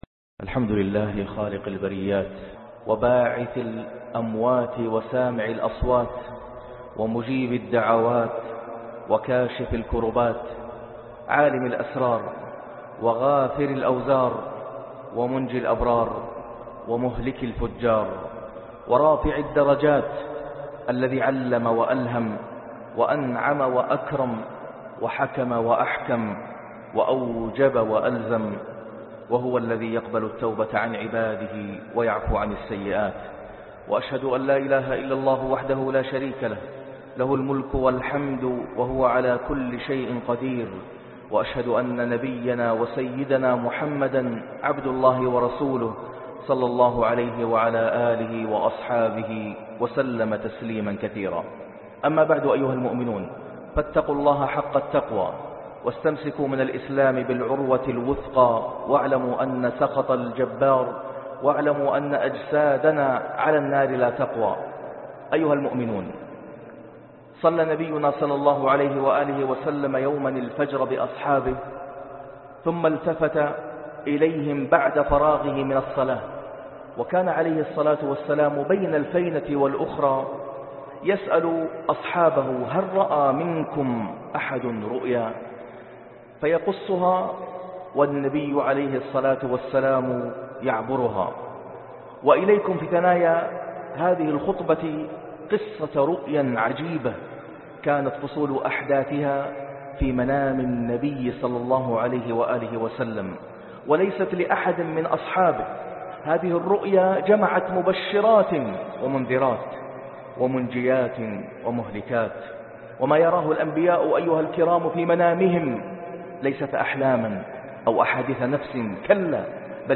انطلق انطلق - خطبة الجمعة